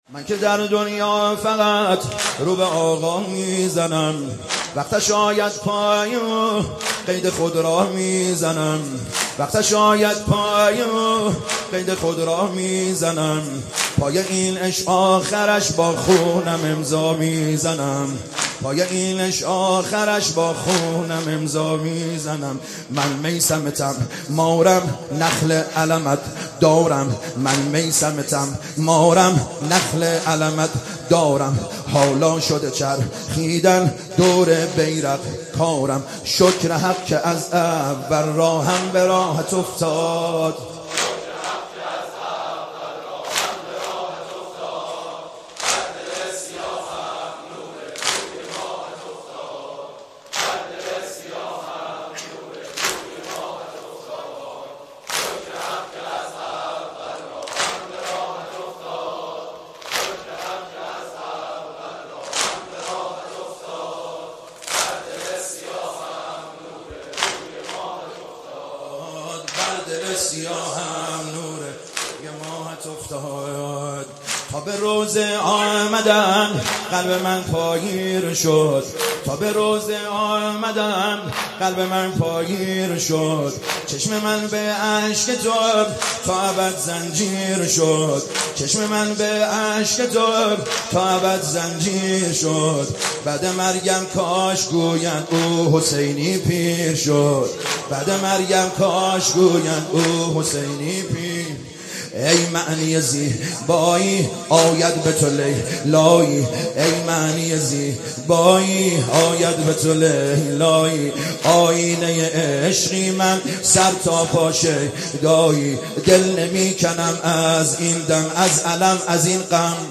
شب سوم محرم97 هیات کربلا رفسنجان